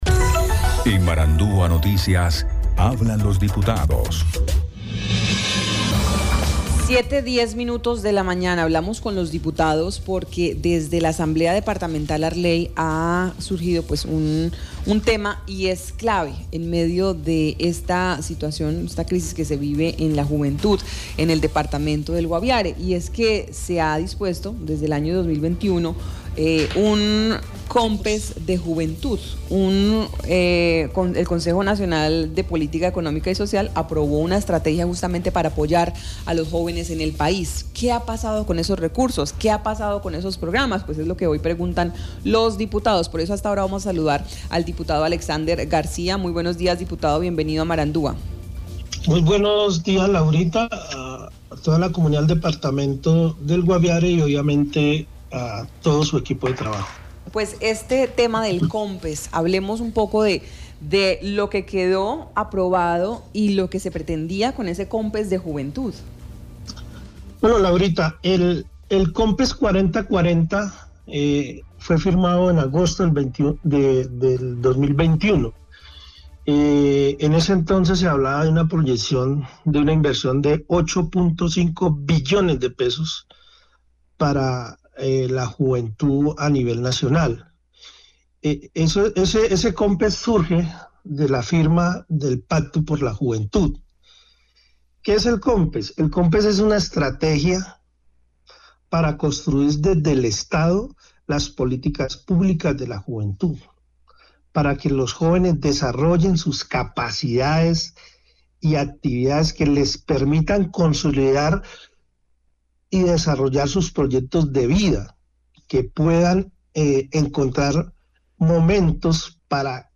¿Qué ha pasado con esos programas juveniles en el Guaviare? Hoy hablamos con el diputado Alexander García.